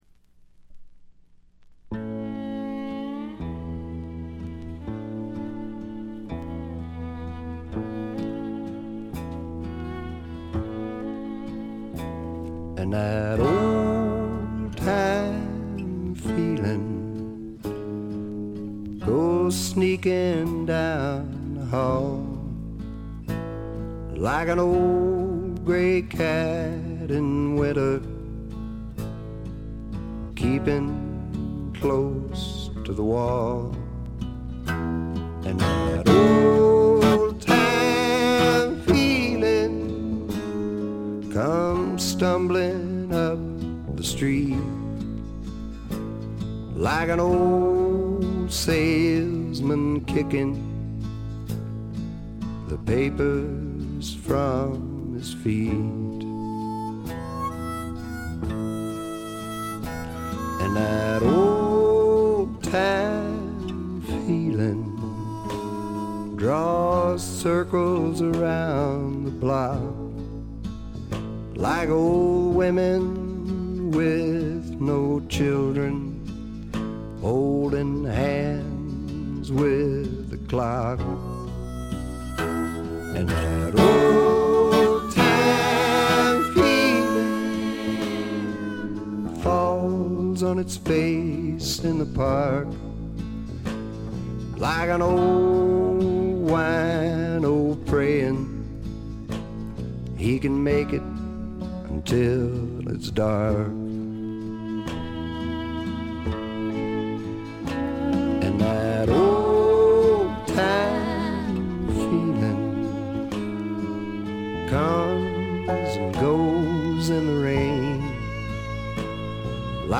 軽微なチリプチ少々。
朴訥な歌い方なのに声に物凄い深さがある感じ。
試聴曲は現品からの取り込み音源です。